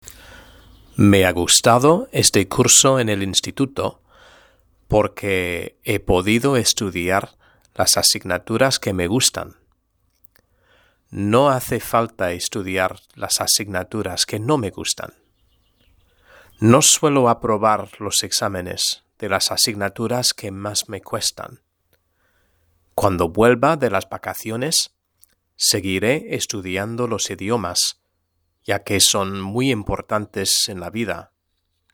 Lectura en voz alta: 1.3 La educación y el trabajo #1 (H)